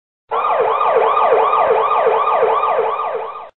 Sirena policia